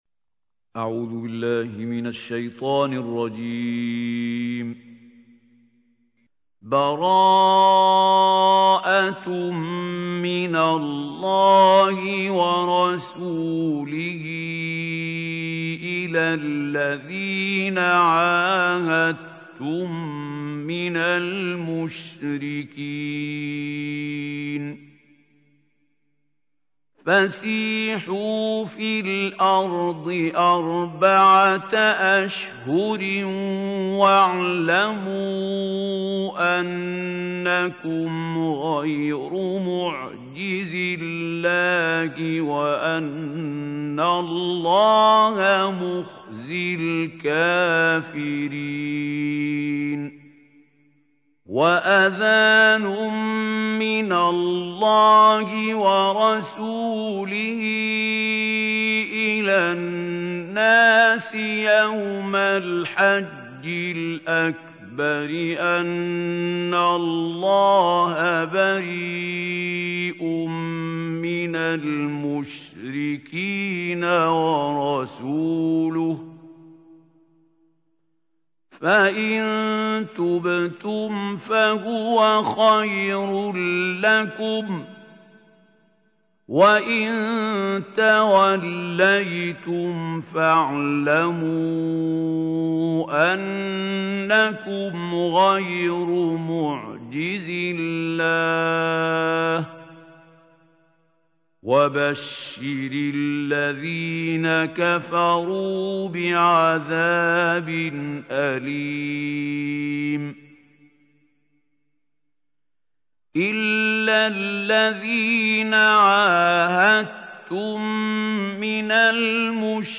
Surah Tauba is 9th chapter of Holy Quran. Listen beautiful recitation of Surah Tawba in the voice of Qari Mahmoud Khalil Al Hussary.